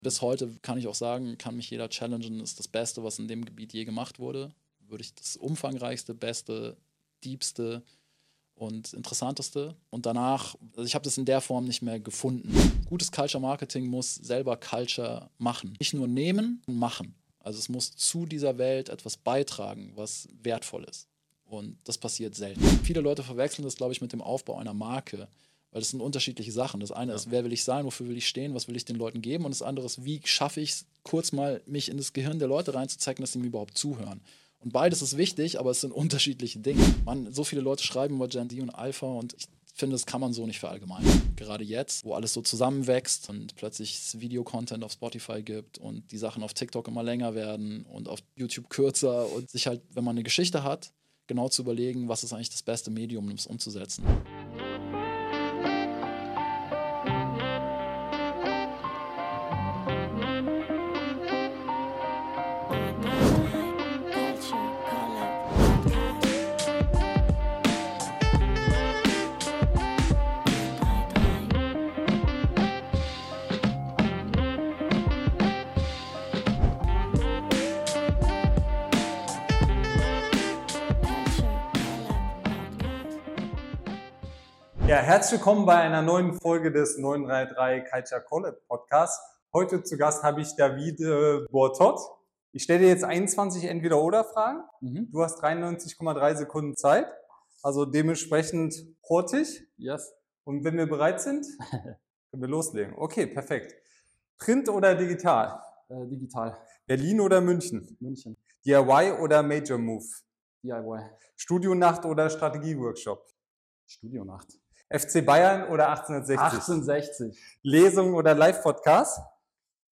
Wir sprechen darüber, warum die Red Bull Music Academy bis heute der Blueprint für Culture Marketing ist, wie Amapiano, UK Funky & Broken Beat eine neue globale Bewegung lostreten und warum Marken häufig scheitern, wenn sie Culture nur imitieren, statt selbst Wert zu schaffen. Ein Gespräch über Community, Challenger Brands, Nostalgie vs. Neugier; und darüber, warum Trend Hopping noch lange keine Marke baut.